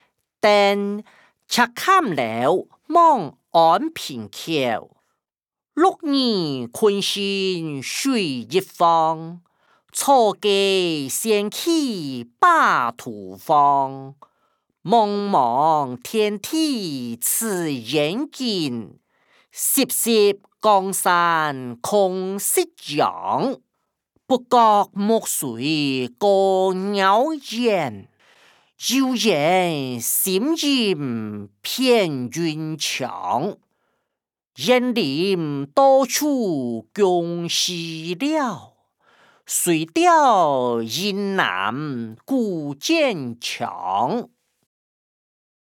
古典詩-登赤嵌樓望安平口音檔(大埔腔)